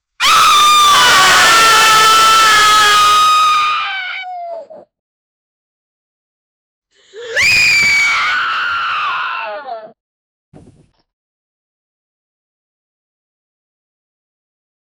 a SFX of all humans screaming and Gunshots of screaming women and crying babies
a-sfx-of-all-humans-j275mwrr.wav